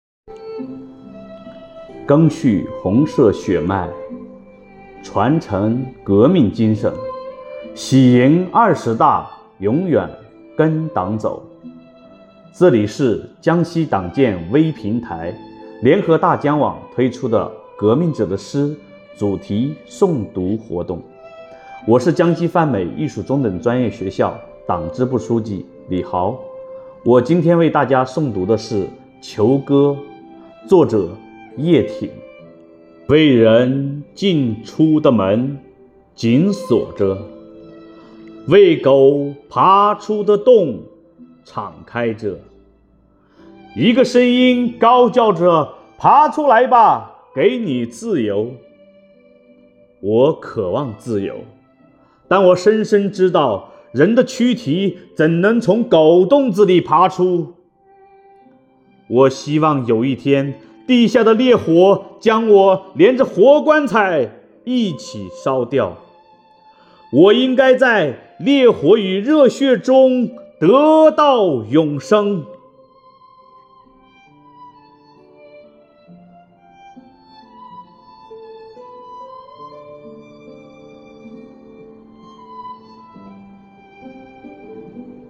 诵读者